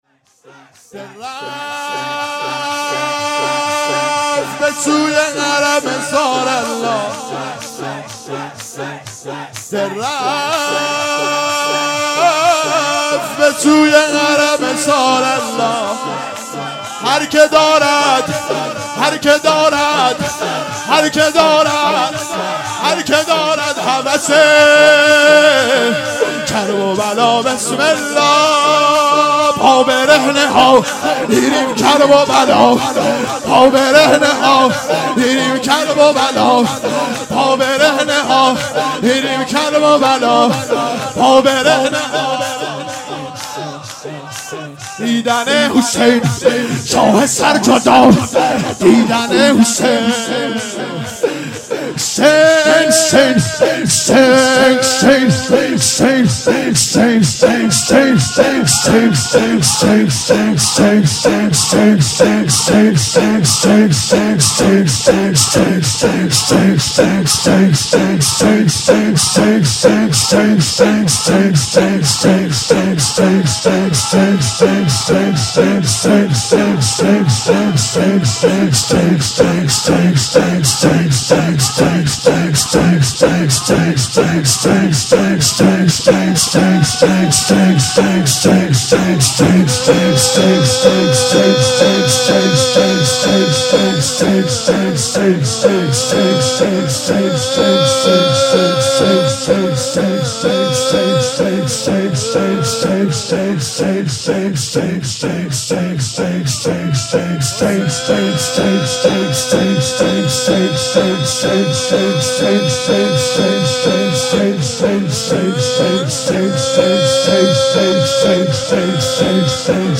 مجموعه صوتی مراسم هفتگی 8 آذرماه 97
ذکر و نوای حسینی